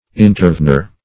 Search Result for " intervener" : The Collaborative International Dictionary of English v.0.48: Intervener \In`ter*ven"er\, n. One who intervenes; especially (Law), a person who assumes a part in a suit between others.
intervener.mp3